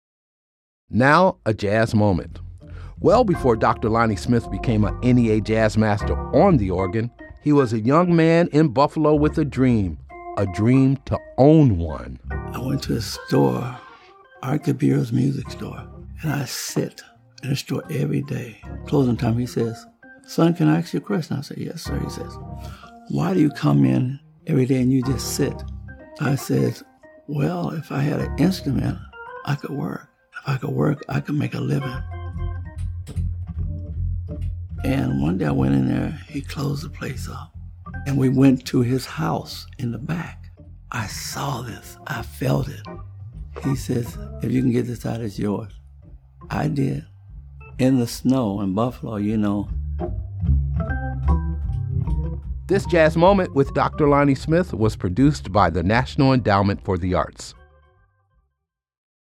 Excerpt of “Too Damn Hot” composed and performed live by Dr. Lonnie Smith, used by permission of DRLS Music.